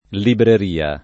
libreria [ librer & a ]